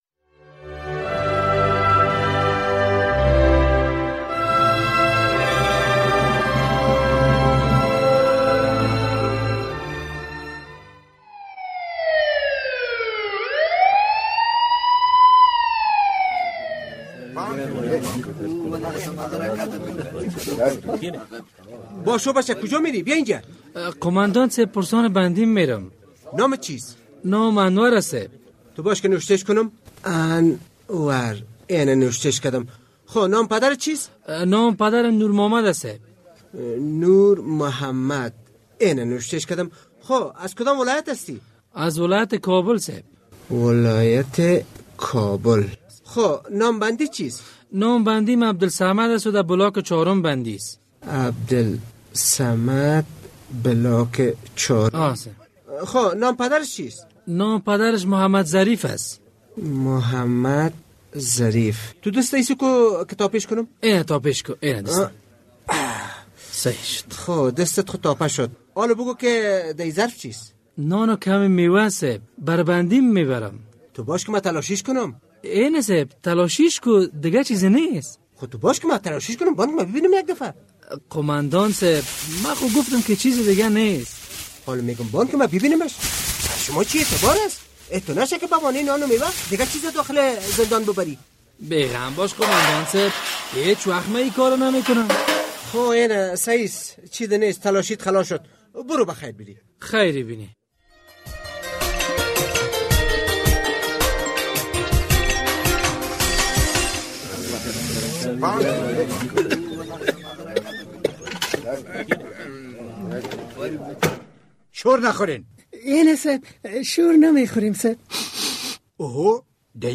درامه: چه کسی و چطور چرس را به داخل زندان آورده‌است؟